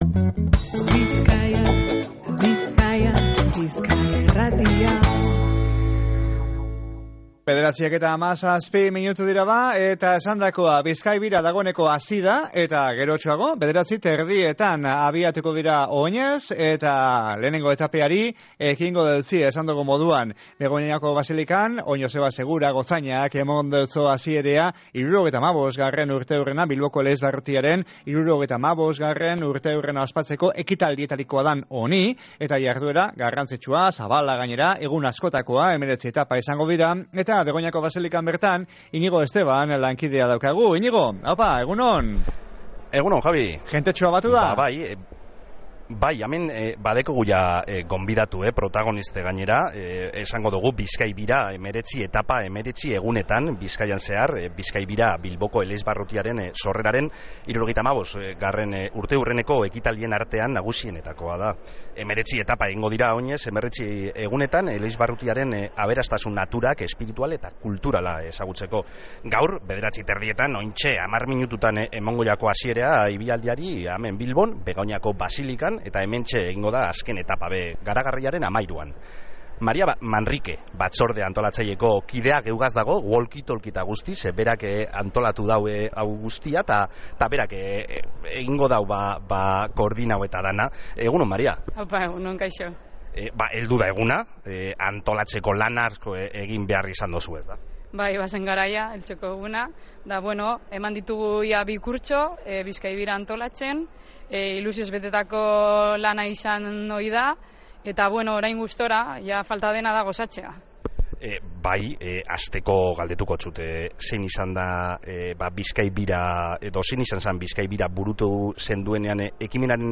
BizkaiBiraren hasierea ikusteko eta parte hartzaileen gogoak eta ilusinoa bizitzeko zuzenean egon gara goizean Begoñako Basilikan egin dan ekitaldian.